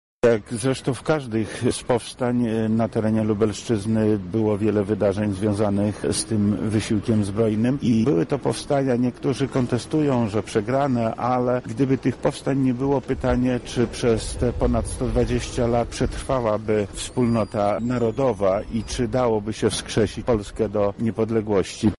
Zapytaliśmy wojewodę lubelskiego Lecha Sprawkę, dlaczego warto obchodzić takie rocznice: